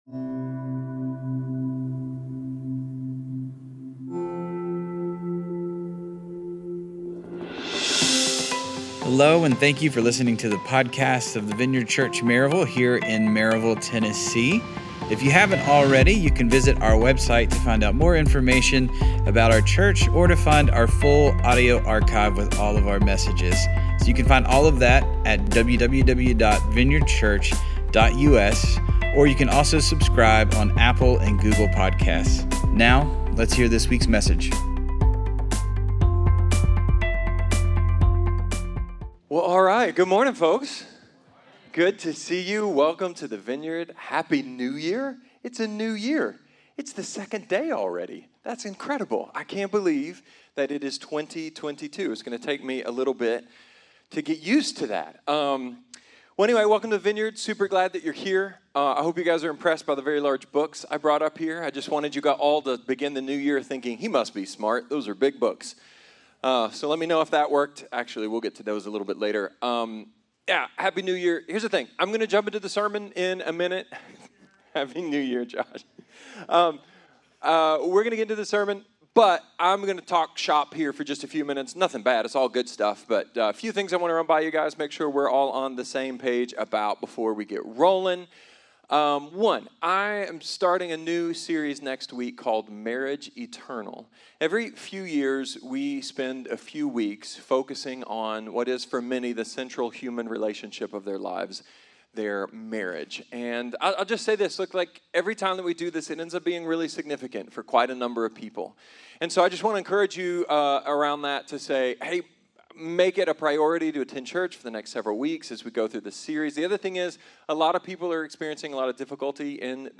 A sermon about forgiveness, shame, judgement, and why what we do now matters for all of eternity.